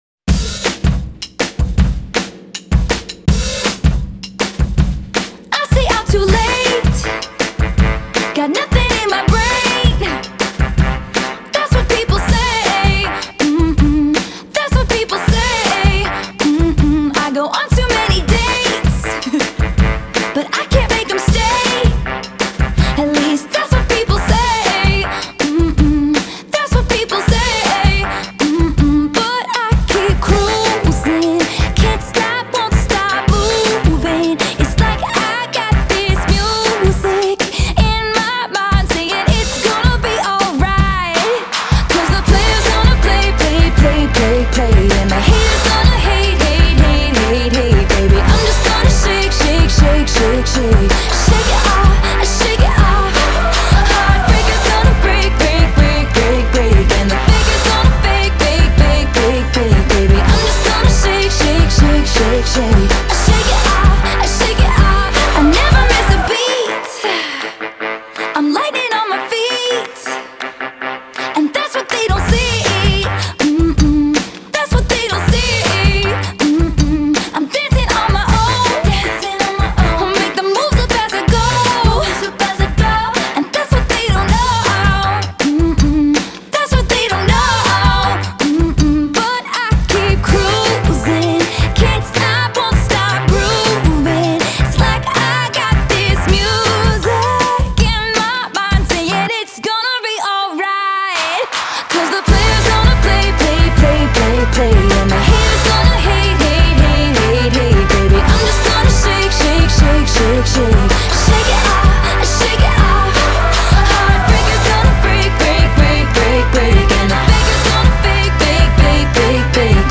ژانر: پاپ / راک